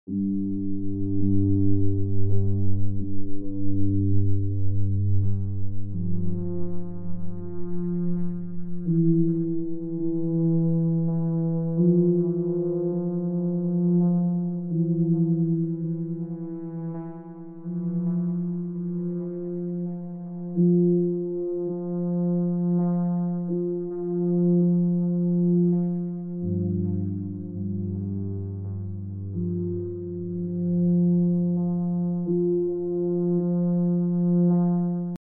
Octave 1
Une note sur 8 : Tous les 4 temps
Durée : 3 temps
Cette méthode est redoutable pour créer des nappes éthérées et instables qui pourraient être facilement intégrables dans des compositions électros plus conventionnelles.